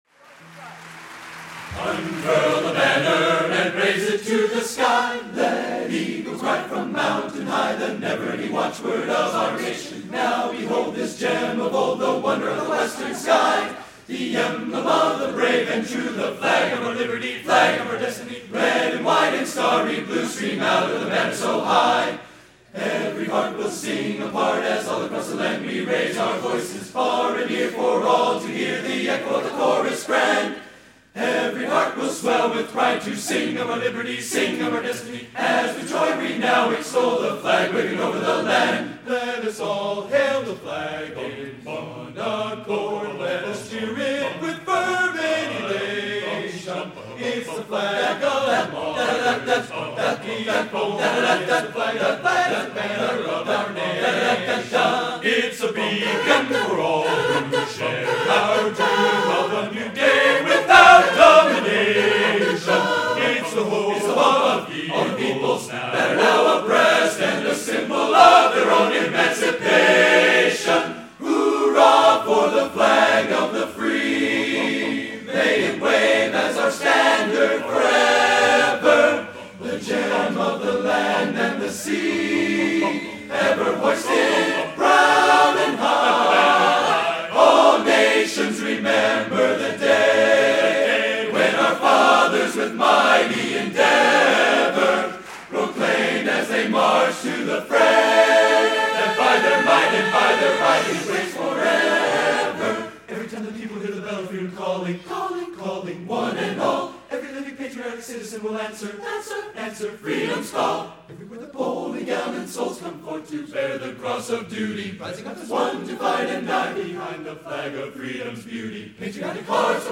Location: Northrop High School, Fort Wayne, Indiana
Genre: Patriotic | Type: